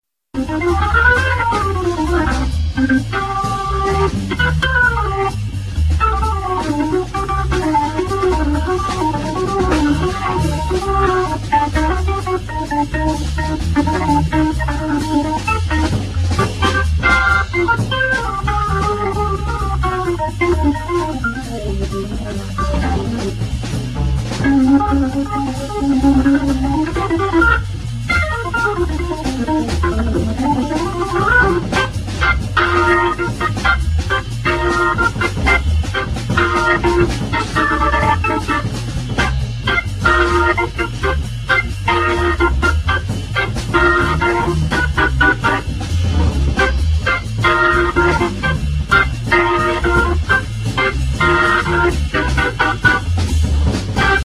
La distorsion c'est comme ça que je l'aime bien.
Distorsion.mp3